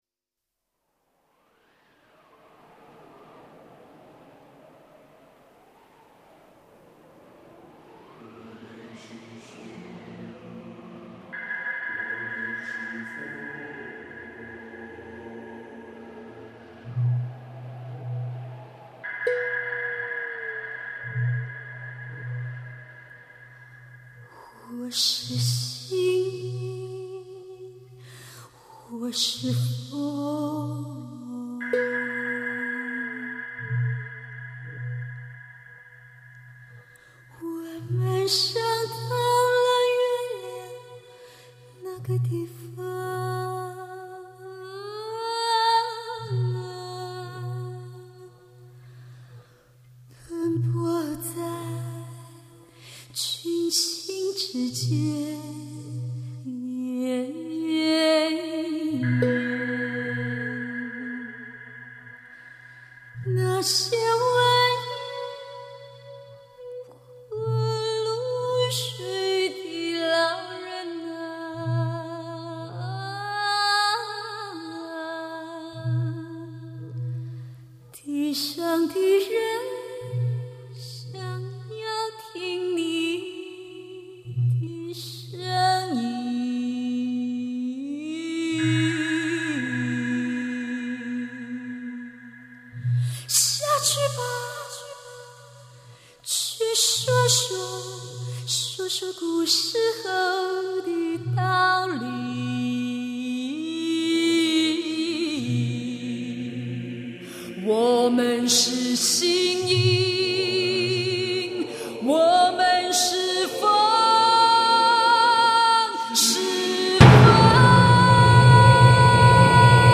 土风+现代
原声乐器+电子MIDI。